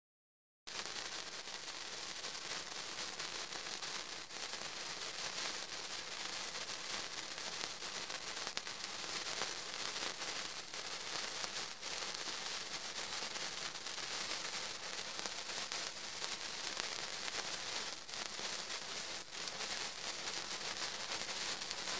Звуки бенгальских огней
Шепот пламени Бенгальского огня